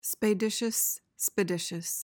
PRONUNCIATION:
(spay/spuh-DISH-uhs)